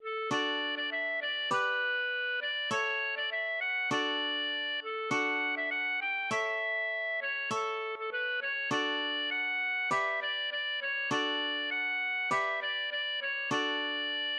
Ein Spottlied
} myMusic = { << \chords { \germanChords \set chordChanges=##t \set Staff.midiInstrument="acoustic guitar (nylon)" s8 d2 g2 a2 d2 d2 a2 a2 d2 g2 d2 g2 d4. } \relative { \time 4/4 \tempo 4=100 \key d \major \partial 8 \set Staff.midiInstrument="Clarinet" a'8 | d8. d16 e8 d8 b4. d8 | cis8. d16 e8 fis8 d4. a8 | fis'8. e16 fis8 g8 e4. cis8 | a8. a16 b8 cis8 d4 fis 4 | e8 d8 d8 cis8 d4 fis 4 | e8 d8 d8 cis8 d4.